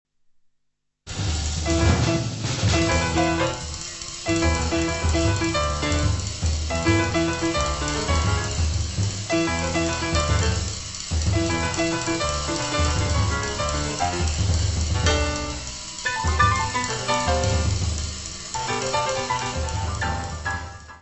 Área:  Novas Linguagens Musicais